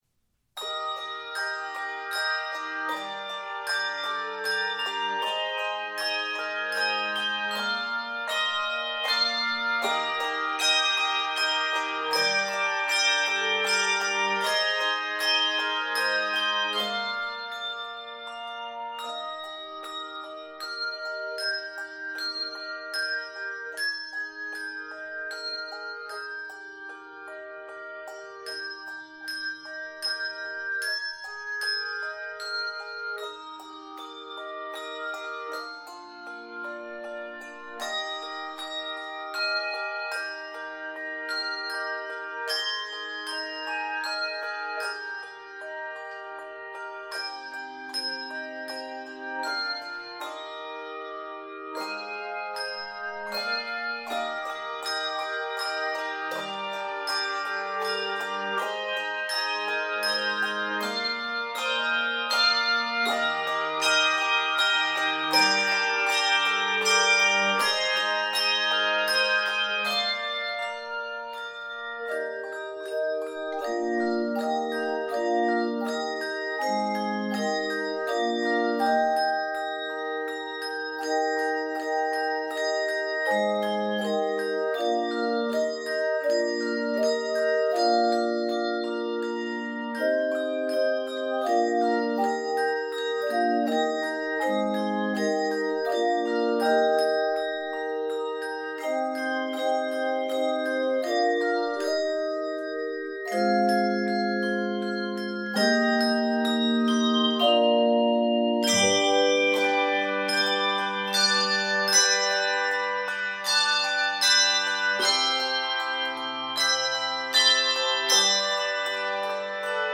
Keys of Eb Major and G Major.